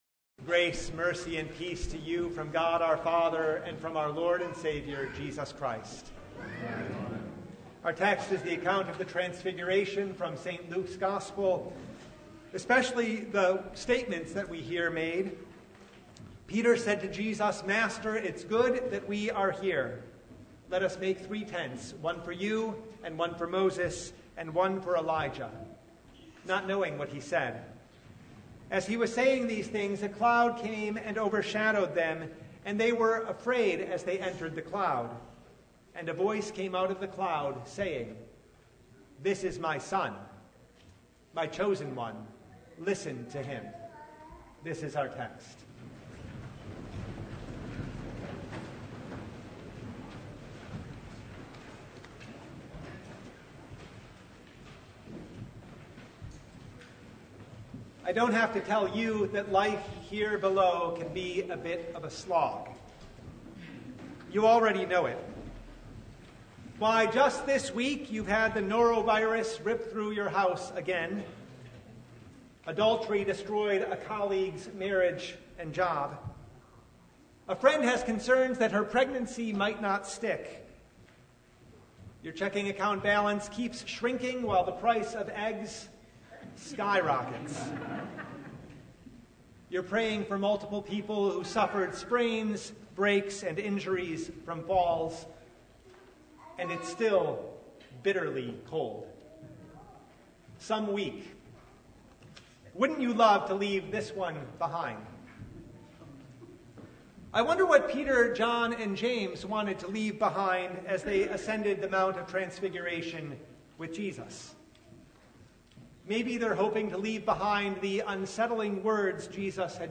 March 2, 2025 Words from the Mountain Passage: Luke 9:28–36 Service Type: Sunday Even as Jesus reveals His glory in the transfiguration, He has a mission to accomplish.